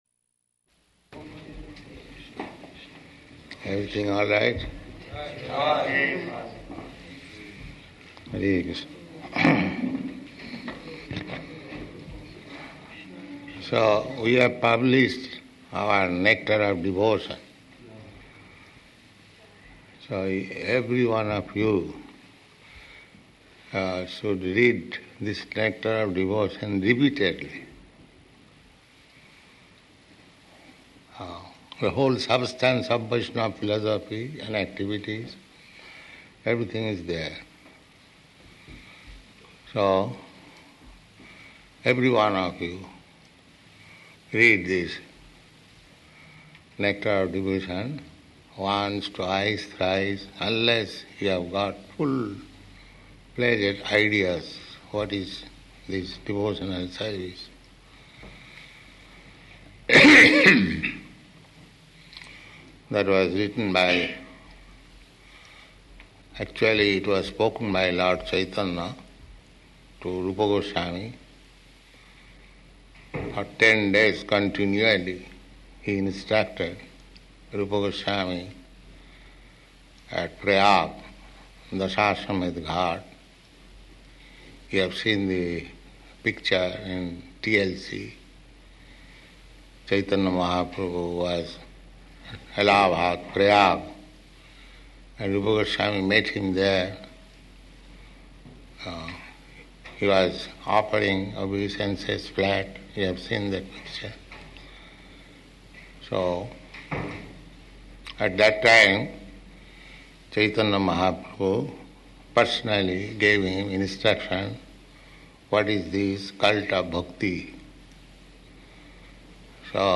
The Nectar of Devotion [First Publication] --:-- --:-- Type: Nectar of Devotion Dated: June 23rd 1970 Location: Los Angeles Audio file: 700623ND-LOS_ANGELES.mp3 Prabhupāda: Everything all right?